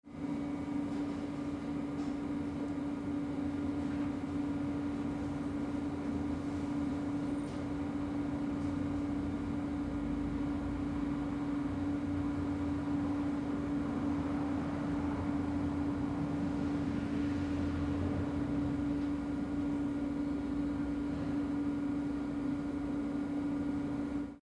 Ambiente interior de una nave con maquinaria ventilando
Sonidos: Industria